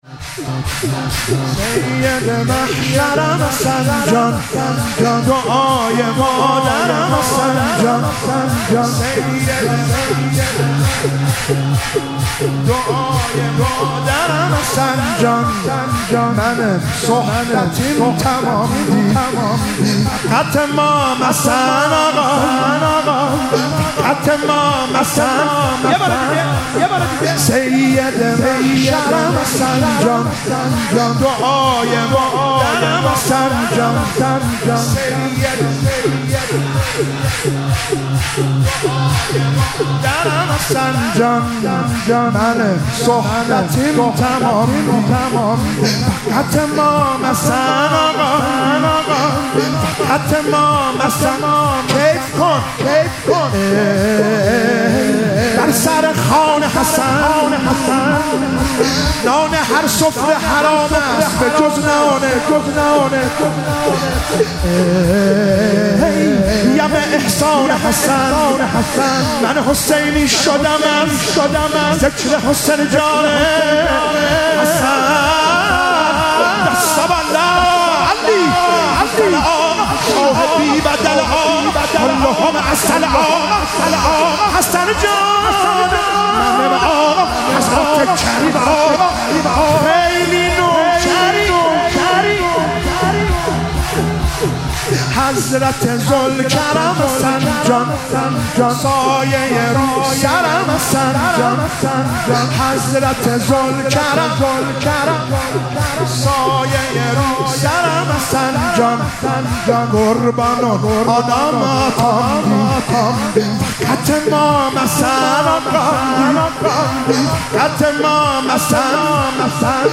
مراسم مناجات خوانی شب شانزدهم و جشن ولادت امام حسن مجتبی علیه السلام ماه رمضان 1444